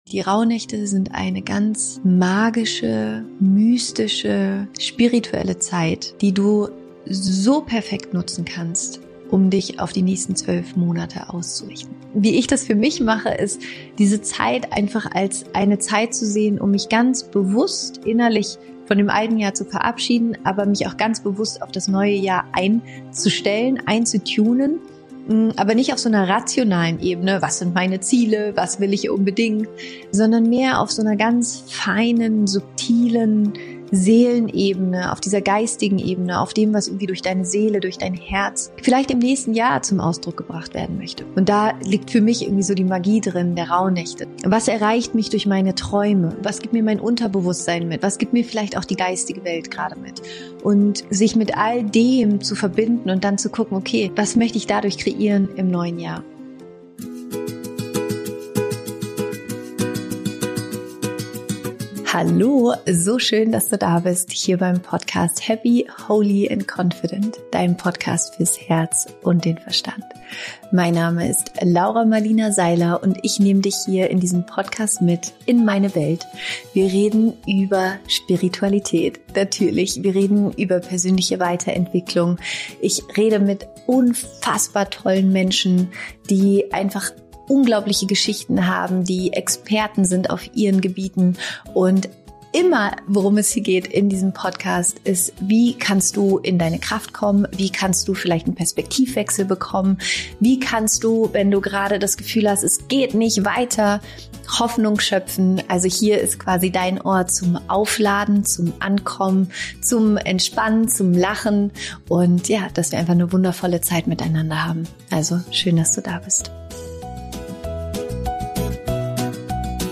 Am Ende der Folge wartet eine wunderschöne Meditation auf dich.